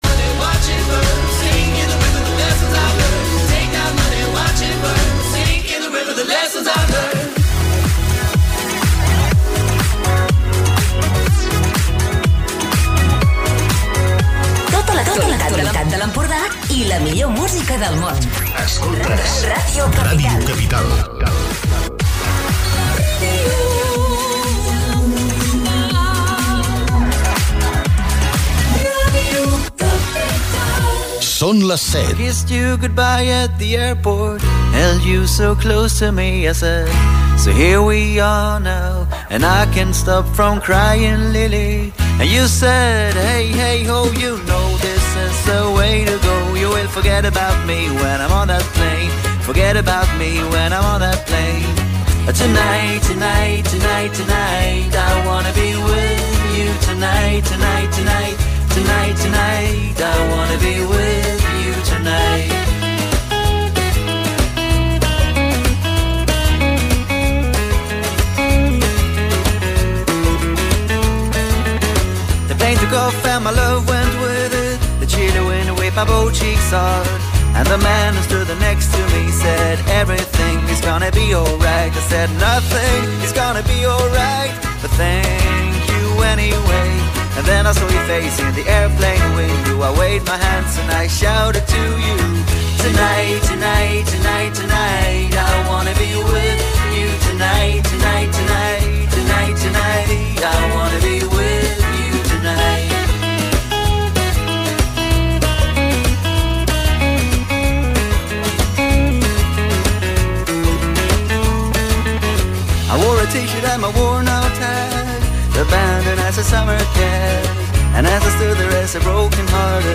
Recupera les tres hores de peticions i bona música: